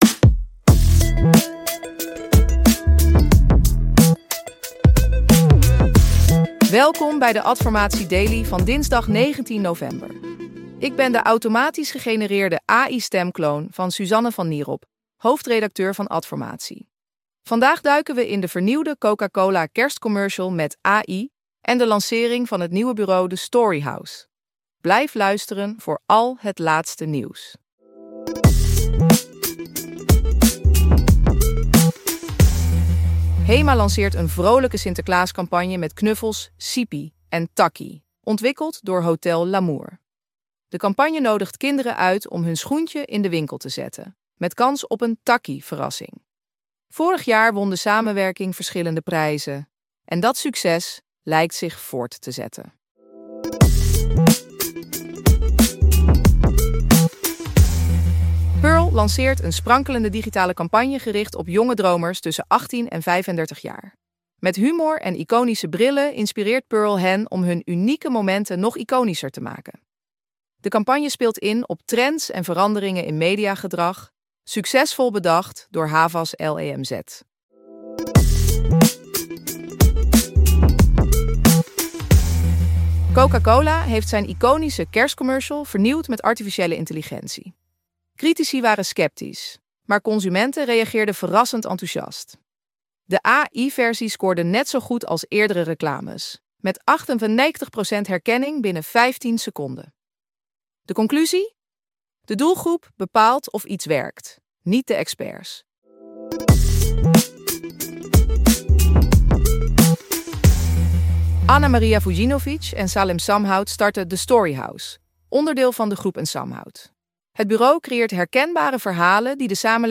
Geïnteresseerd in een automatisch gegenereerde podcast voor jouw content?